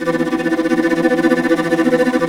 SI2 VIOLIN02.wav